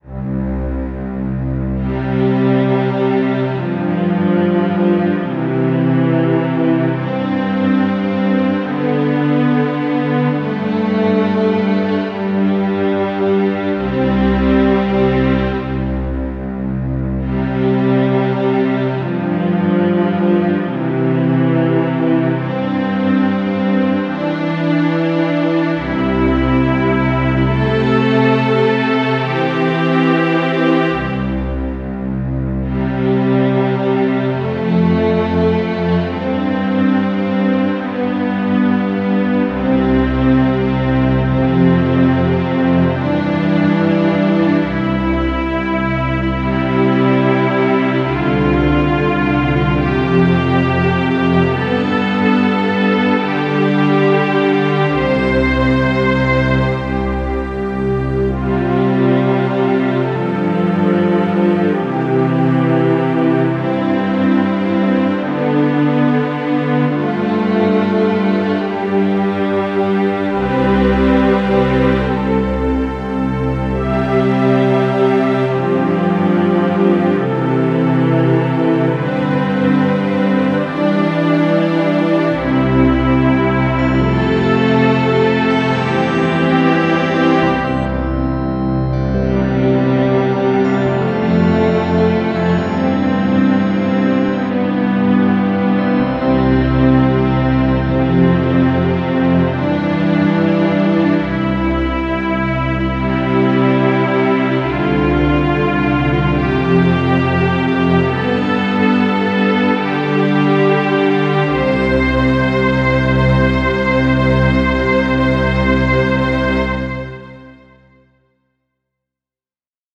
symphonic